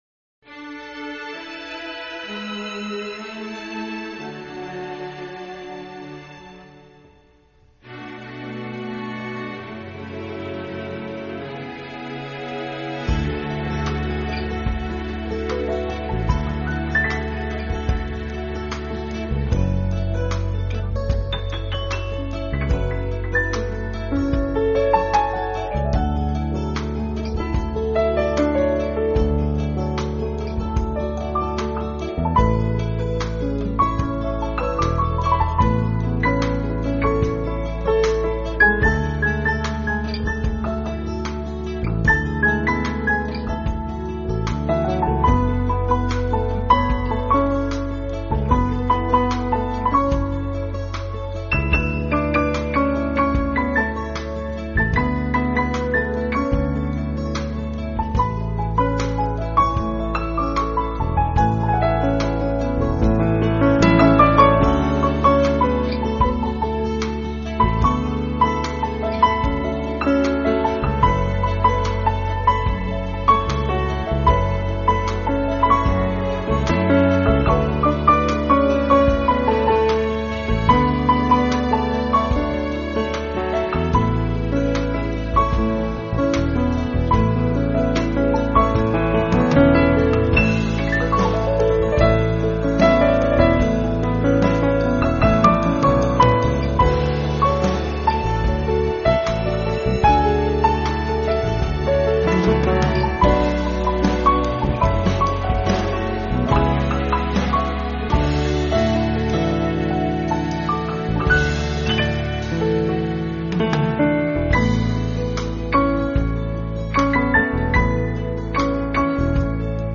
他们把这些原本流行的歌曲改编为带有明显爵士味道的纯音乐，
拥有了爵士音乐特有的浪漫，却没有爵士音乐的吃力和震荡，
这些作品却显得那么的舒缓和柔美，轻盈飘荡间，